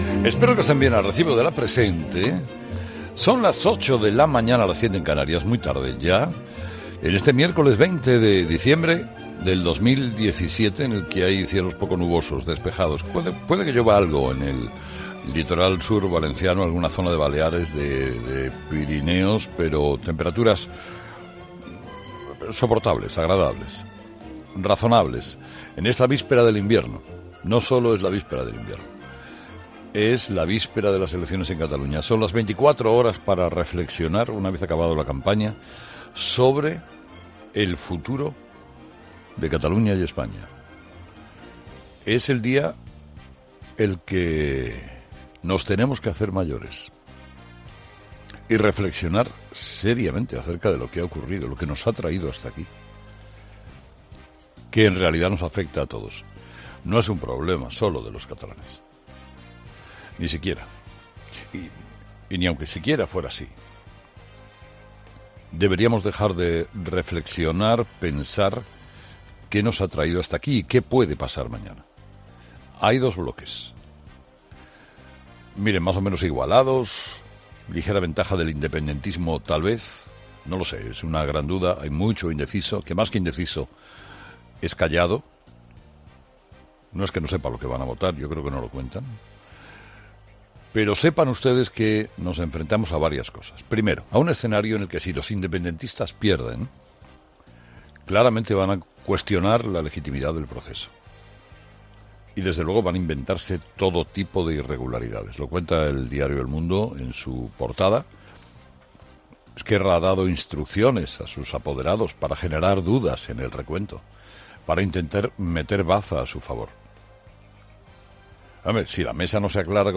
Monólogo de las 8 de Herrera 'Herrera en COPE